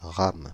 Prononciation
Synonymes mémoire vive Prononciation France (Île-de-France): IPA: /ʁam/ Le mot recherché trouvé avec ces langues de source: français Les traductions n’ont pas été trouvées pour la langue de destination choisie.